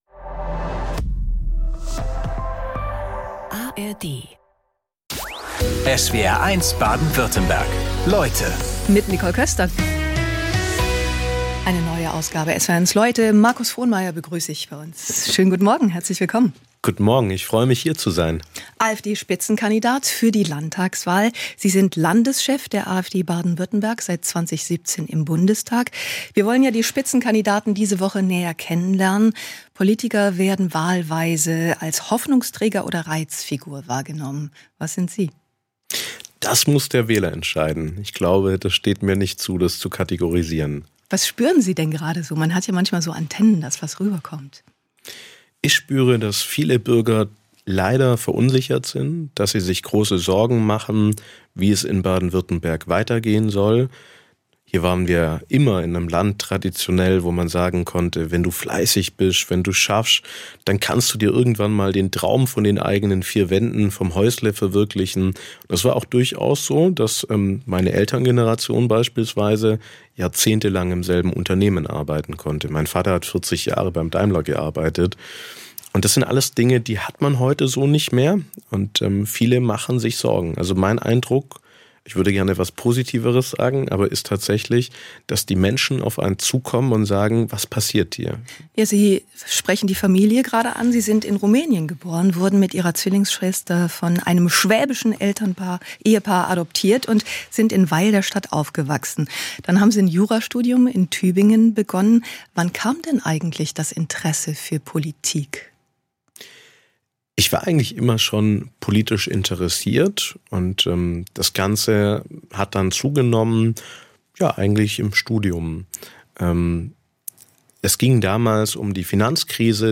Vor der Landtagswahl 2026 sind Spitzenpolitiker:innen aus Baden-Württemberg zu Gast in SWR1 Leute, unter anderem Markus Frohnmaier von der AfD.